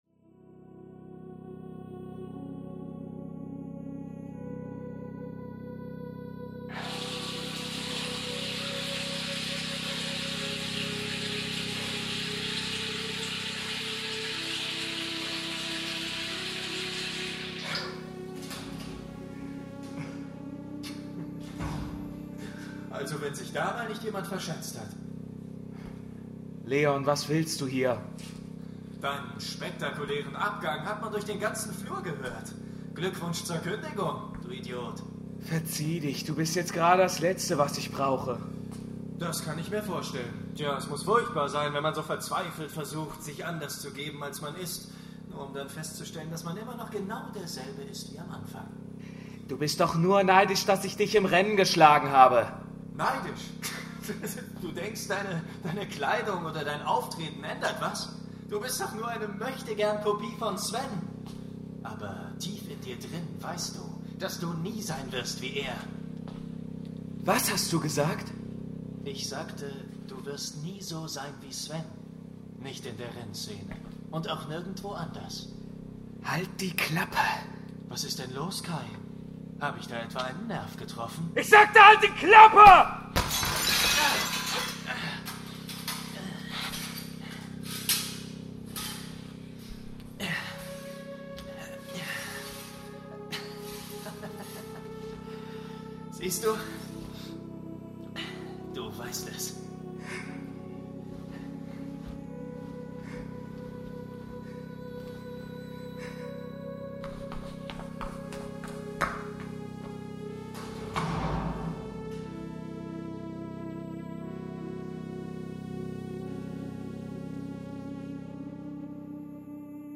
Binaurales Hörspiel
„Siegesrausch“ ist ein 60-minütiges Hörspiel im Genre des Actiondramas, welches durch seine binaurale Abmischung eine immersive 3D-Klanglandschaft für die Hörer*innen erschafft.